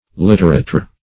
Litterateur \Lit`te`ra`teur"\ (l[-e]`t[asl]`r[.a]`t[~e]r"), n.